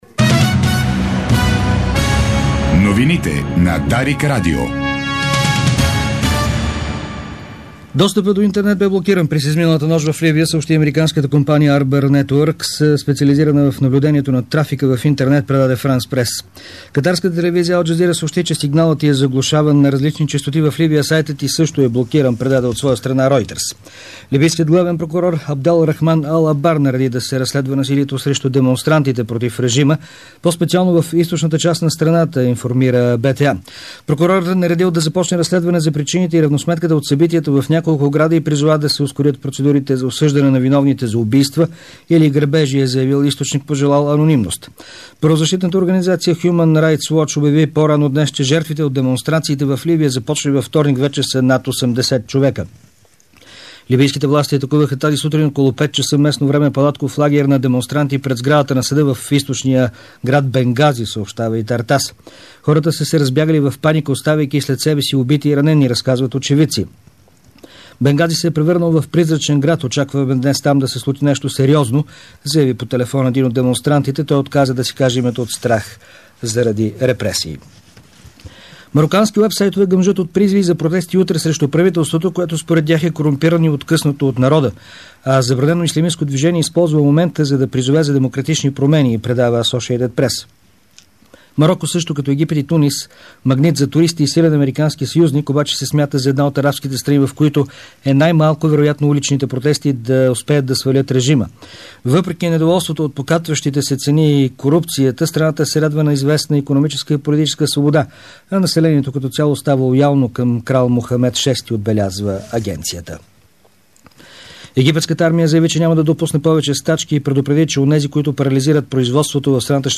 Обедна информaционна емисия - 19.02.2011